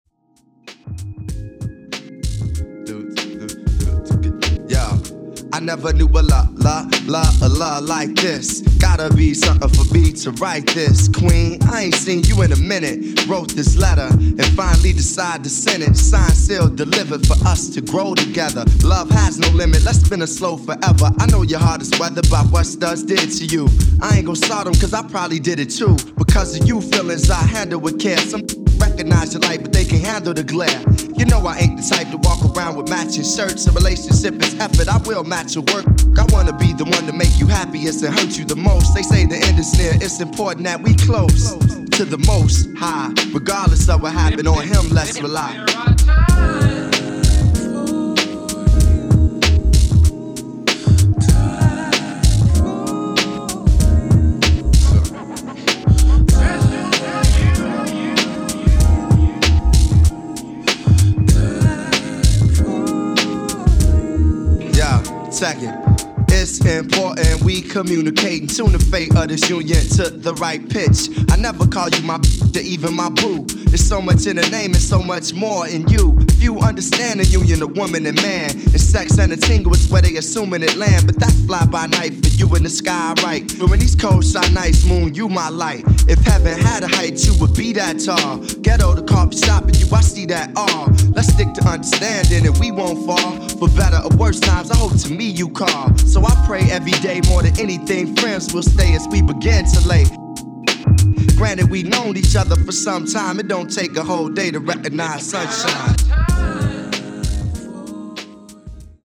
Hit-n-run blends and vinyl only amends.